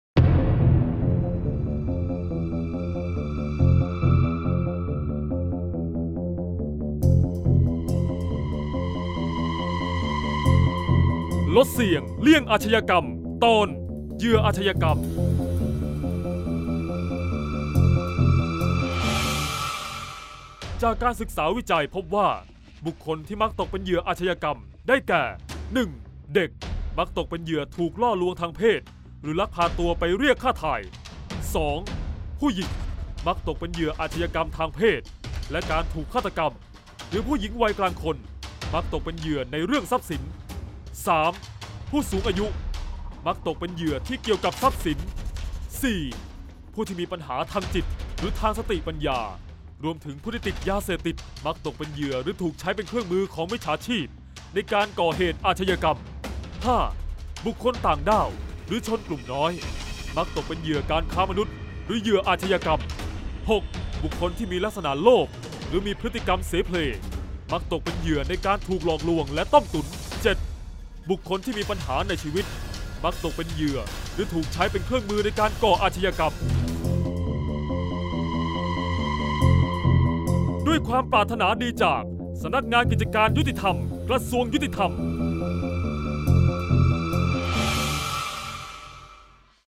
ชื่อเรื่อง : เสียงบรรยาย ลดเสี่ยงเลี่ยงอาชญากรรม 31-เหยื่ออาชญากรรม